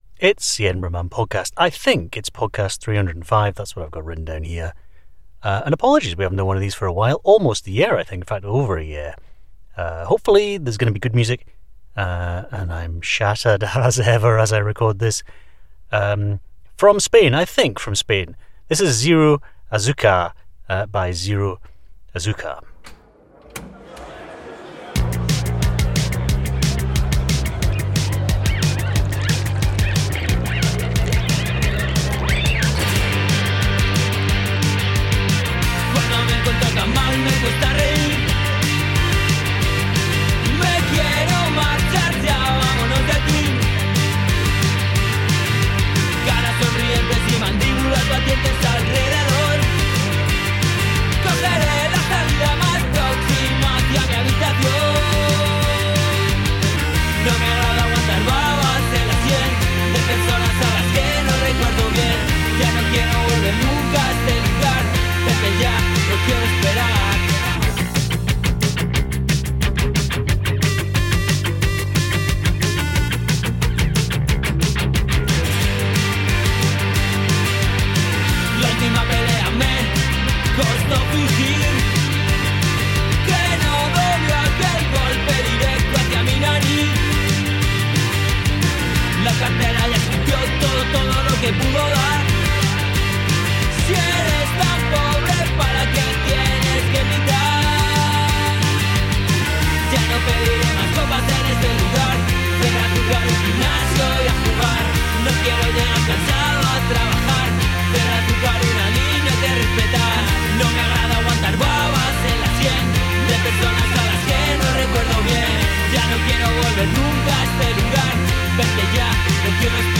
An indie music podcast. Made in Edinburgh in Scotland, but with podsafe music from all over the world.